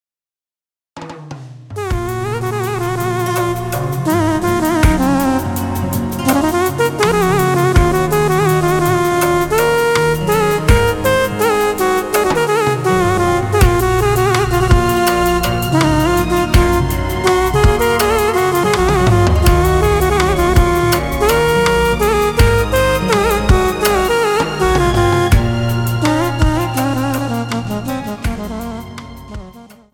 • Type : Instrumental
• Bpm : Andante
• Genre : Turkish style/ Fusion